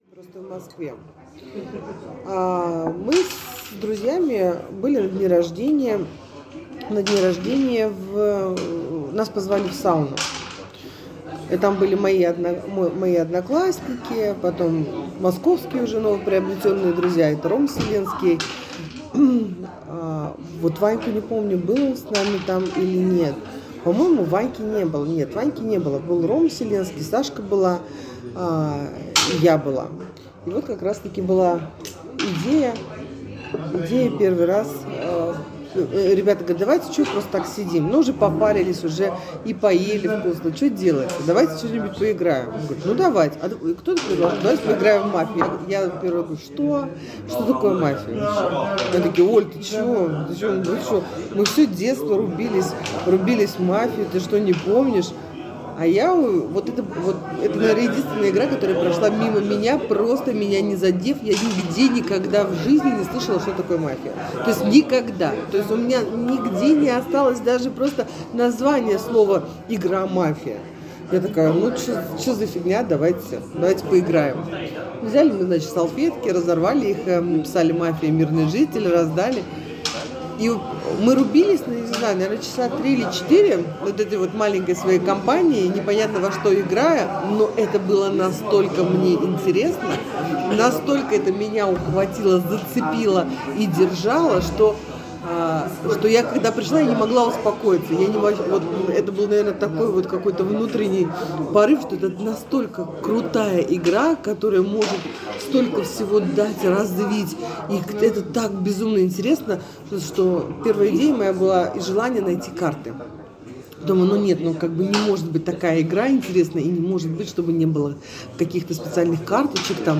Фрагмент интервью: